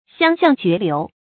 香象絕流 注音： ㄒㄧㄤ ㄒㄧㄤˋ ㄐㄩㄝˊ ㄌㄧㄨˊ 讀音讀法： 意思解釋： 見「香象渡河」。